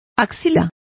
Complete with pronunciation of the translation of armpits.